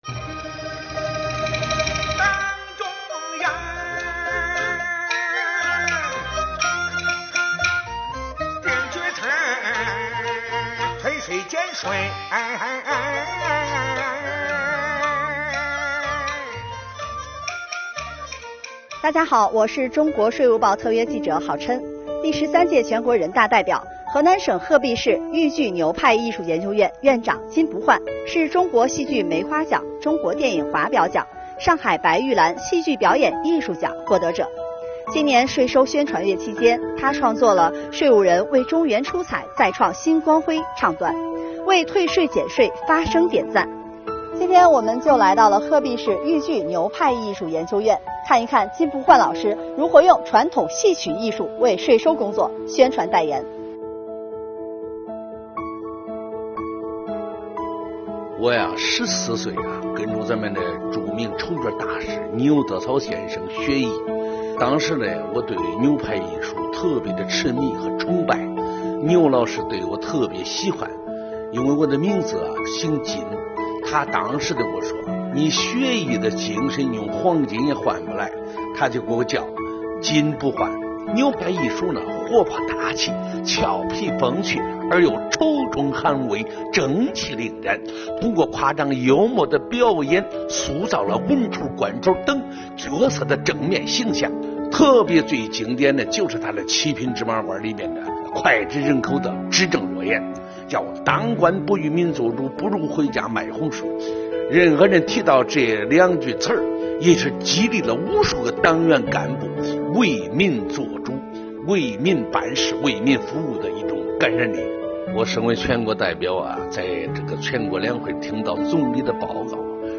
特别是2018年当选为全国人大代表后 ，金不换广泛征集群众意见，用一件件议案反映民情民声；借助豫剧传统表演形式传递国家的好政策，用乡音乡情唱好退税减税大戏。